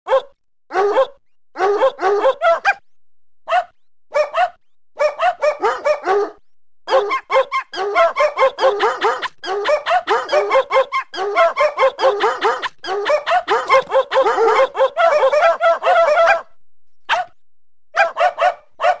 Kategorien: Tierstimmen